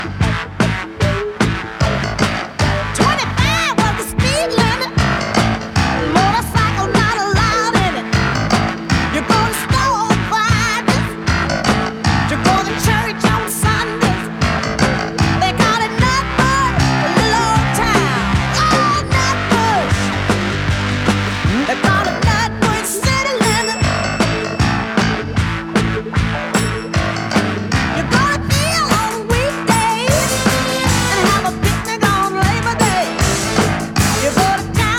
Жанр: Соул